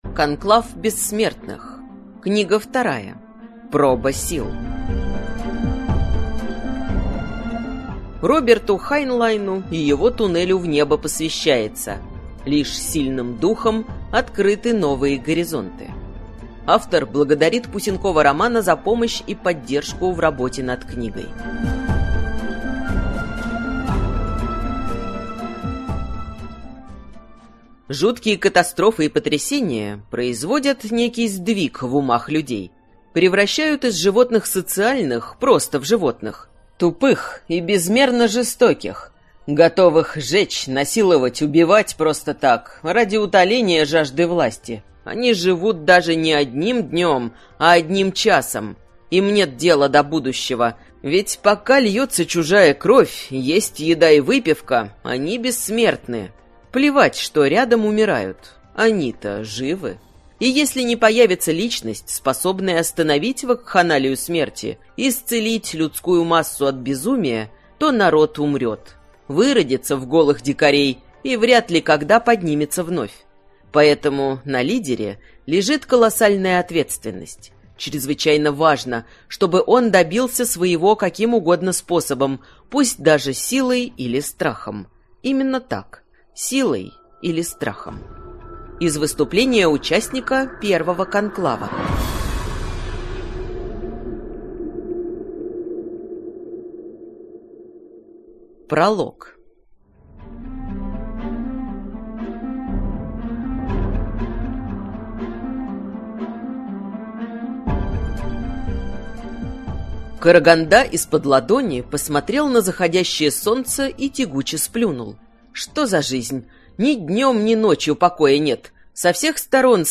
Аудиокнига Конклав Бессмертных. Проба сил | Библиотека аудиокниг